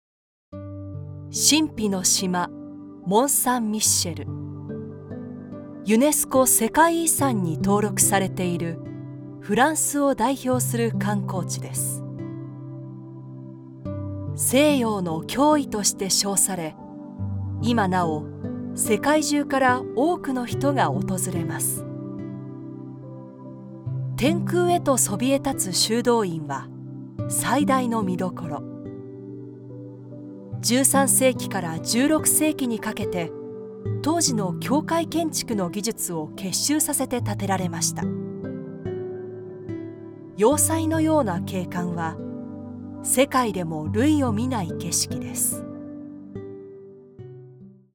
Kommerziell, Tief, Zuverlässig, Freundlich, Corporate
Audioguide
Her voice is versatile, stylish, luxurious, authentic, yet believable